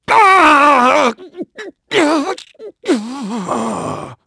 Clause-Vox-Story-Endure_2.wav